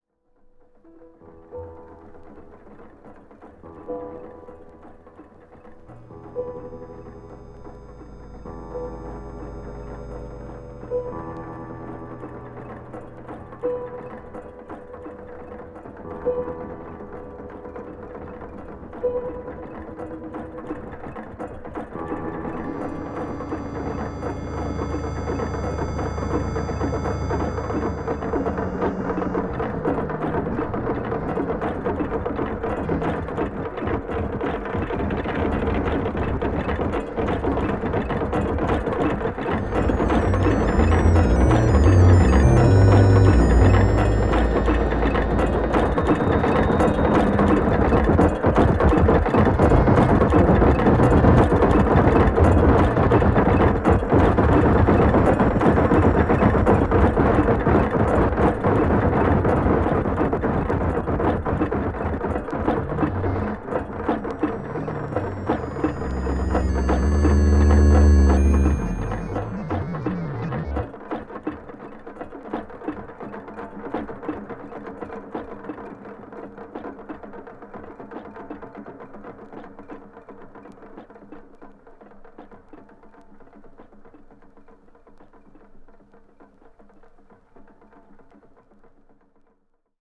writhing electronic sounds
concrète sounds resembling radio broadcast recordings